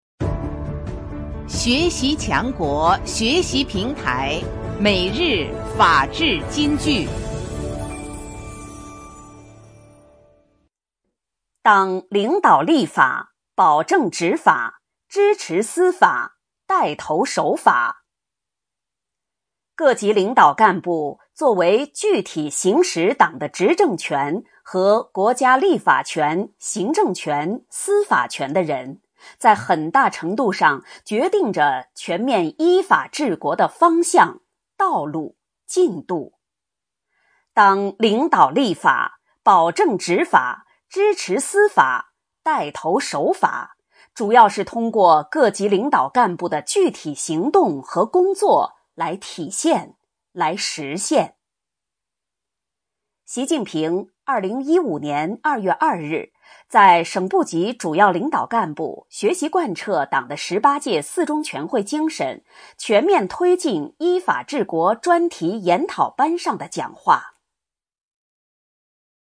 每日法治金句（朗读版）|党领导立法、保证执法、支持司法、带头守法 _ 学习宣传 _ 福建省民政厅